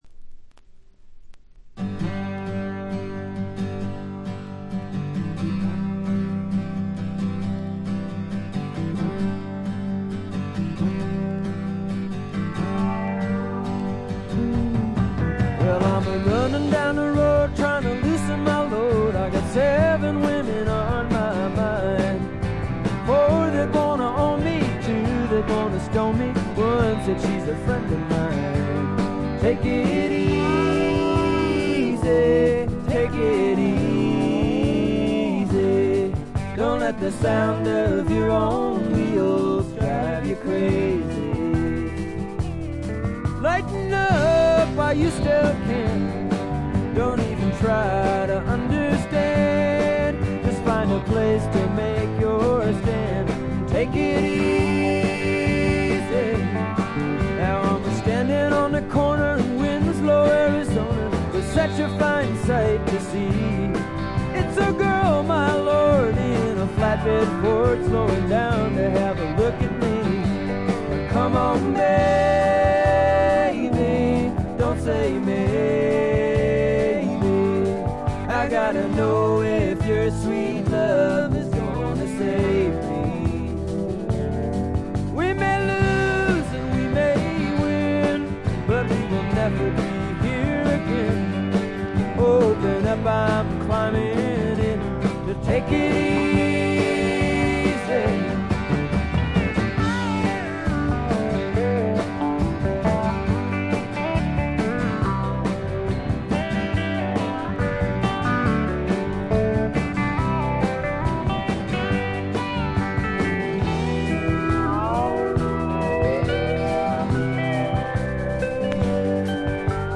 A4で3連プツ音、これ以外にも散発的なプツ音少し。ところどころでチリプチ。
試聴曲は現品からの取り込み音源です。